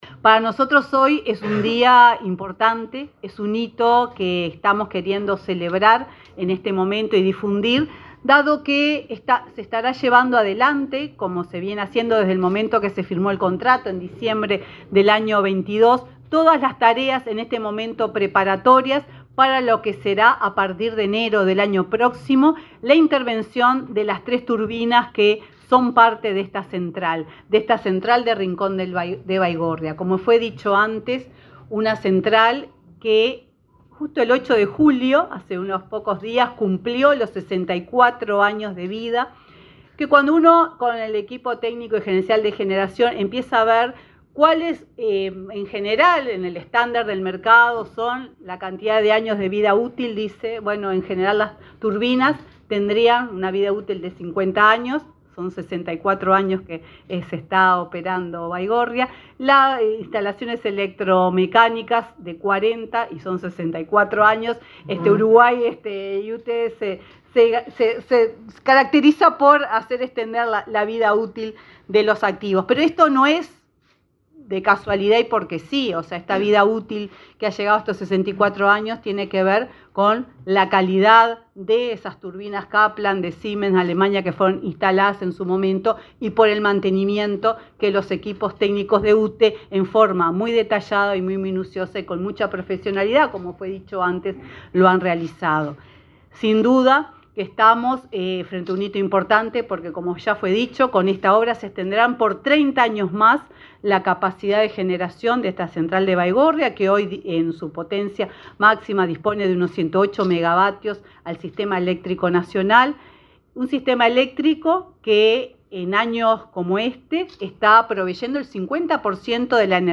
Palabras de la presidenta de UTE, Silvia Emaldi
La presidenta de la UTE, Silvia Emaldi, encabezó, este miércoles 10 en el Palacio de la Luz, la presentación del proyecto de renovación de la central